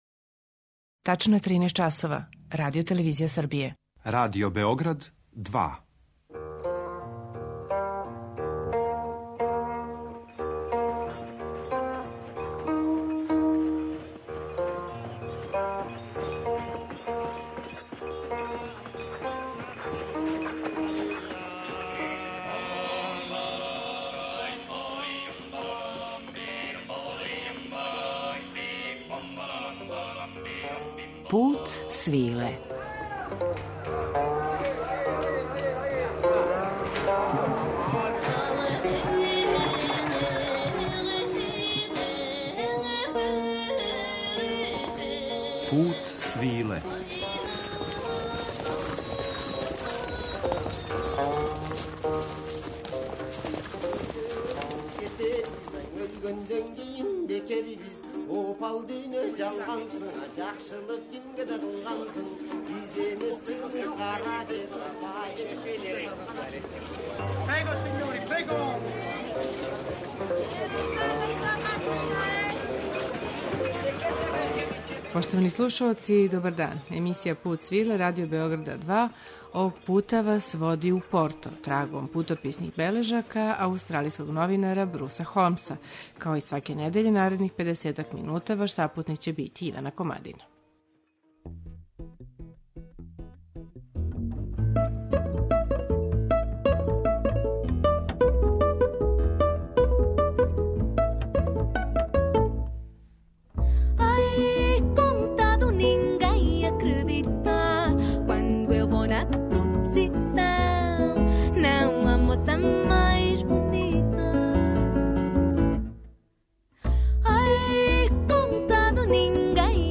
Атмосфера Порта уз музику младих португалских уметника